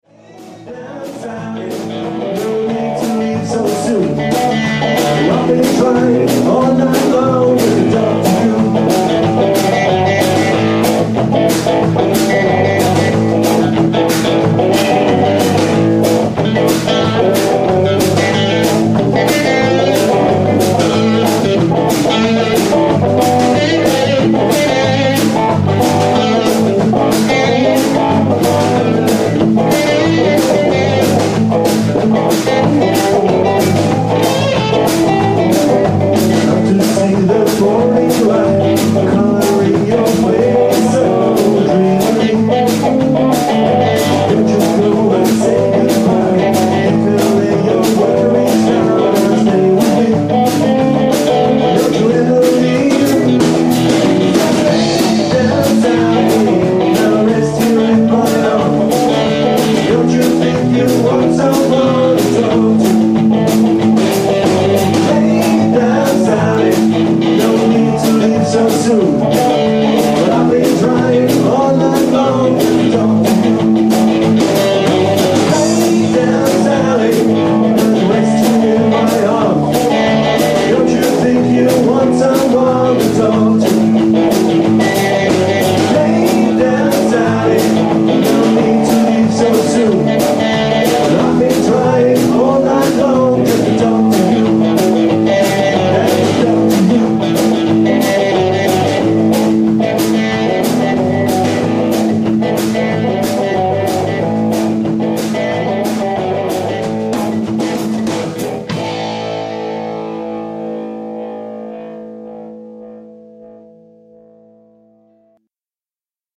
Yhtye
cover-kappaleita